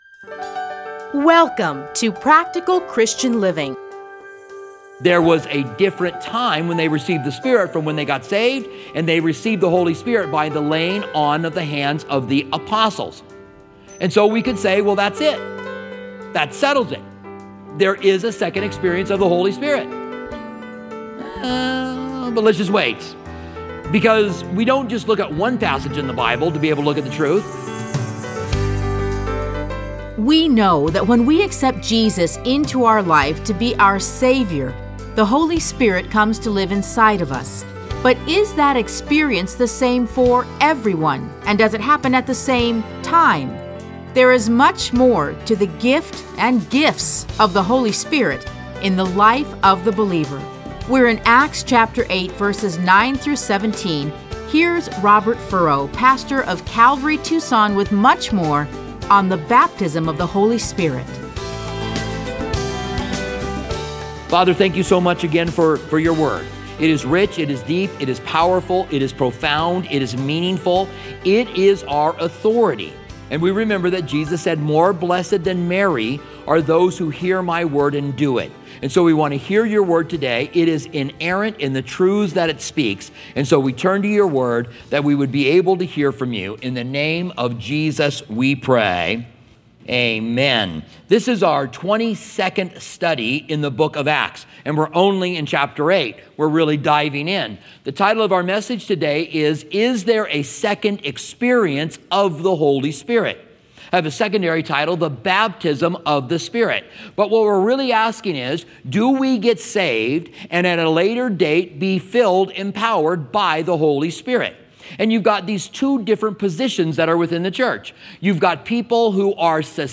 Listen to a teaching from Acts 8:9-17.